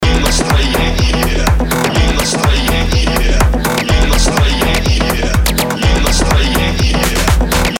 Уже свел, отмастерил, думаю дай озон повешу, что он покажет, и он мне поднимает низы от 54.